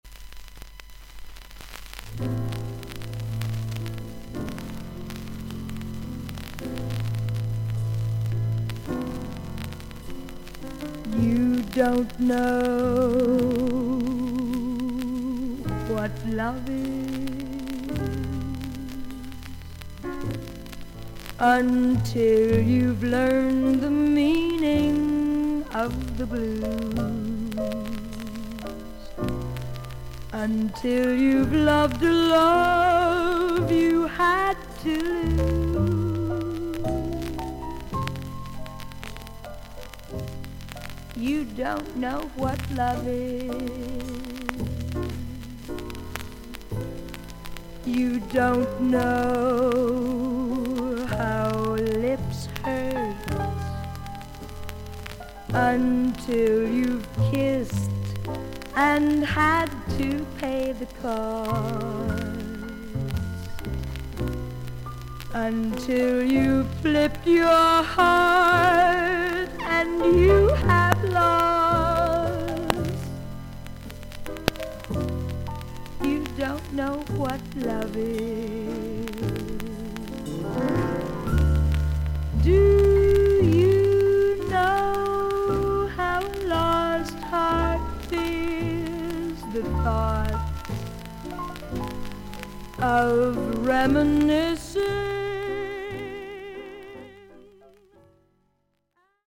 レコード： VG+〜VG 盤面にいくつかキズがあり、所々に少々軽いパチノイズの箇所あり。
全体的にサーフィス・ノイズが大きめにあります。
【コメント】 ハスキー・ヴォイスの女性ジャズ・シンガー。